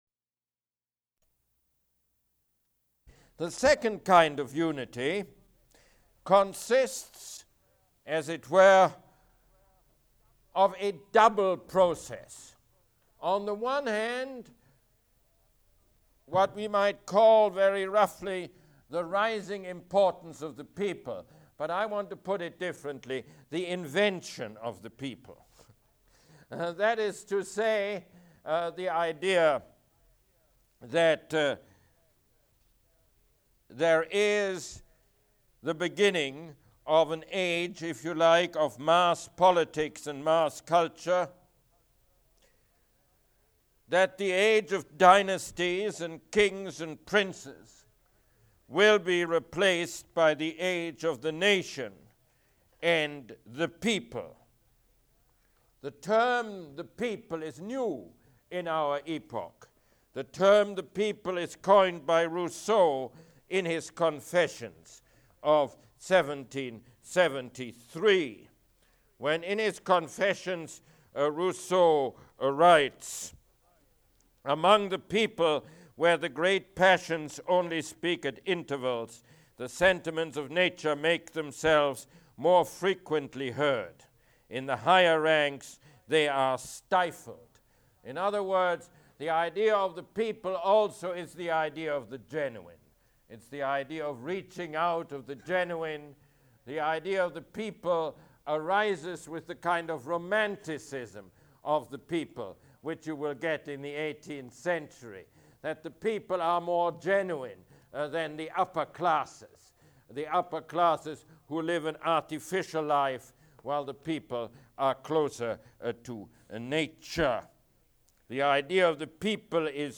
Mosse Lecture #1